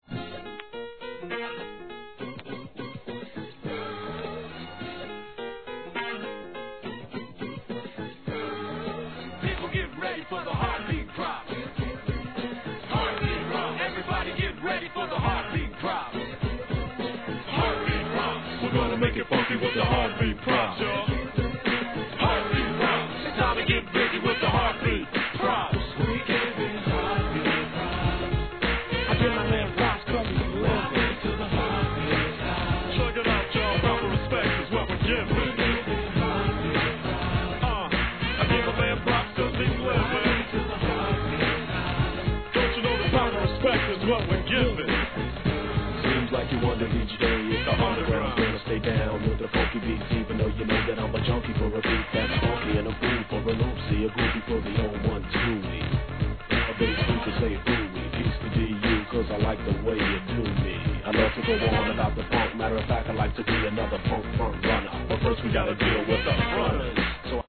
HIP HOP/R&B
P-Funk